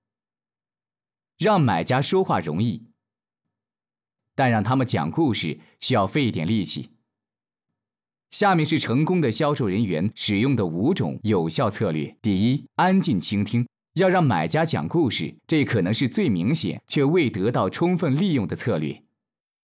Chinese_Male_049VoiceArtist_2Hours_High_Quality_Voice_Dataset